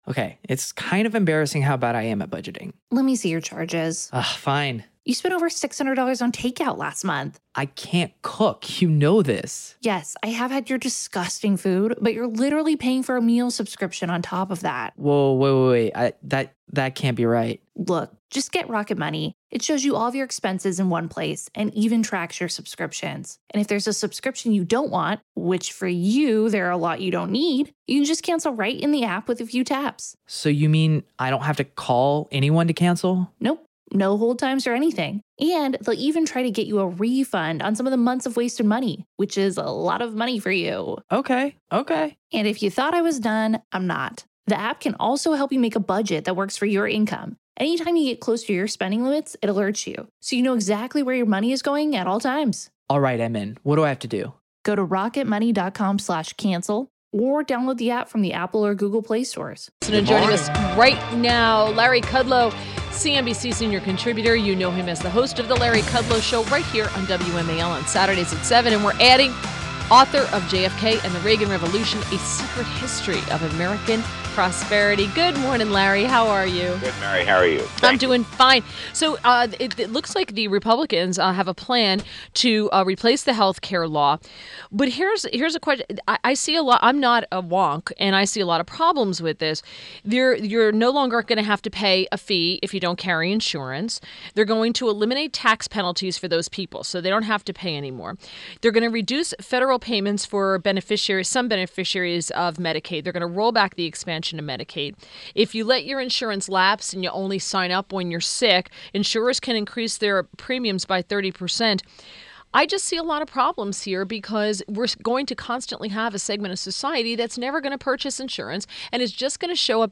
WMAL Interview - LARRY KUDLOW - 03.07.17